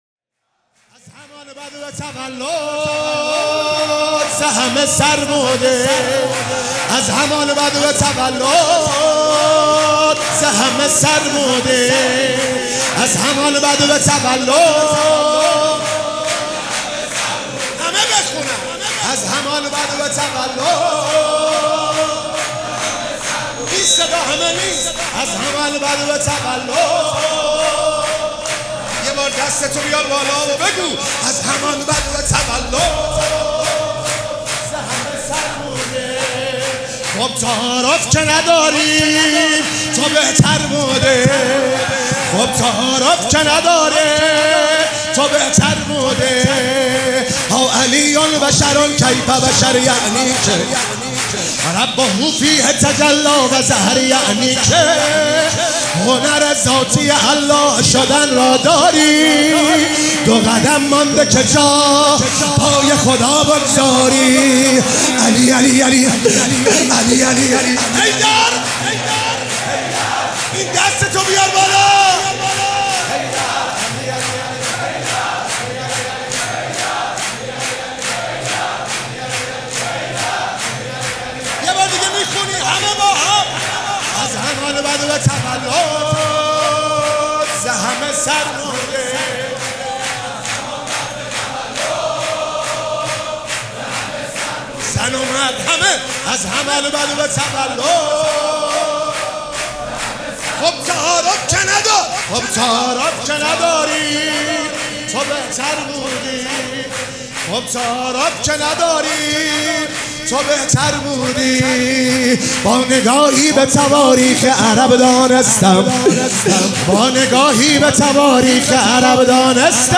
مراسم جشن ولادت حضرت زینب (سلام الله علیها)
‌‌‌‌‌‌‌‌‌‌‌‌‌حسینیه ریحانه الحسین سلام الله علیها
شور